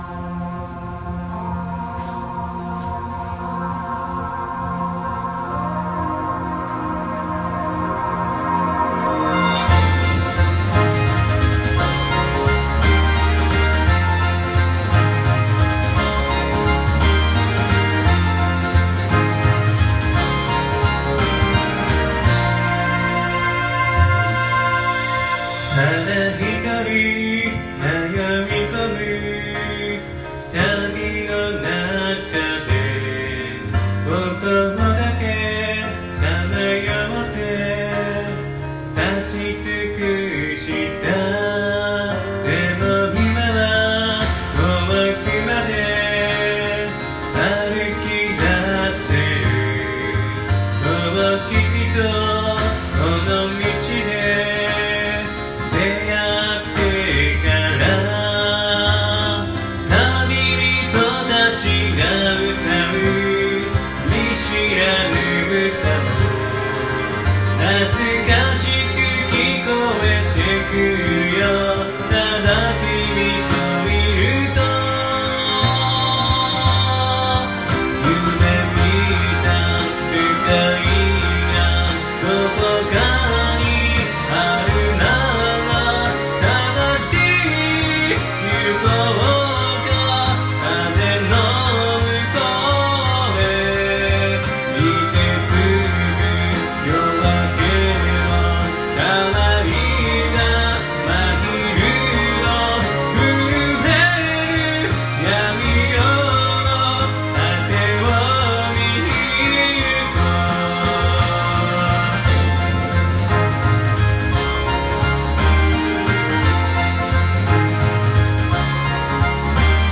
노래방 올리는 애 있길래 따라 올려봄